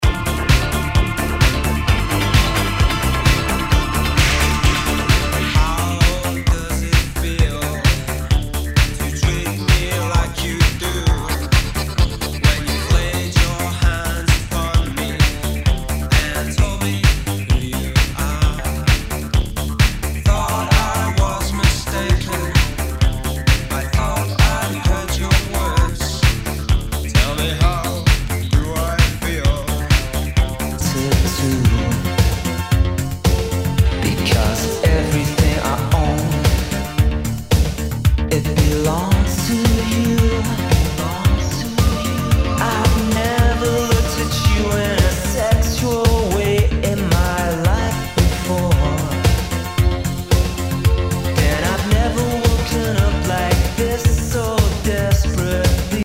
SOUL/FUNK/DISCO
ナイス！シンセ・ポップ・ディスコ！